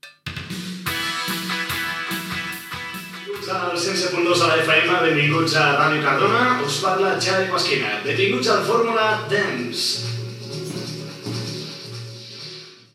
Identificació del programa i benvinguda.
Musical